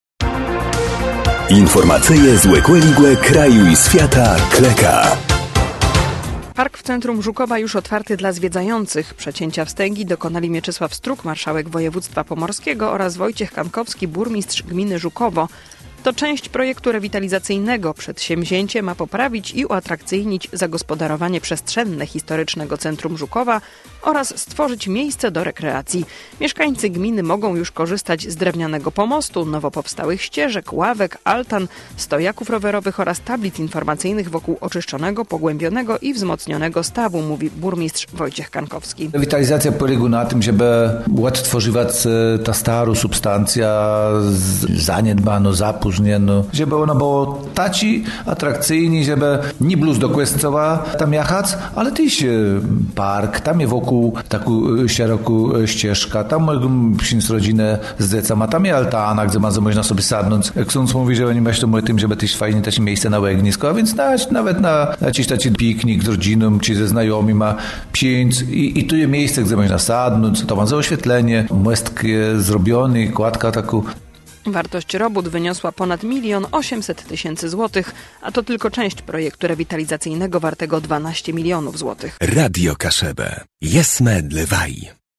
Przedsięwzięcie ma poprawić i uatrakcyjnić zagospodarowanie przestrzenne historycznego centrum Żukowa oraz stworzyć miejsce do rekreacji. Mieszkańcy gminy mogą już korzystać z drewnianego pomostu, nowo powstałych ścieżek, ławek, altan, stojaków rowerowych oraz tablic informacyjnych wokół oczyszczonego, pogłębionego i wzmocnionego stawu, mówi burmistrz Wojciech Kankowski.